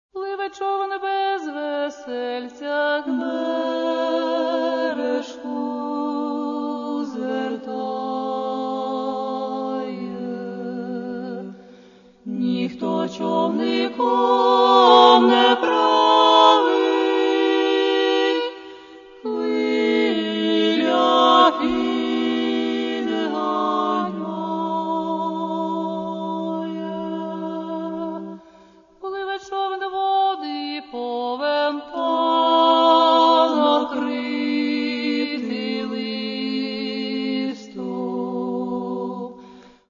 Каталог -> Народна -> Солоспіви та хори
У співі хору – і міцна сила, і широкий простір.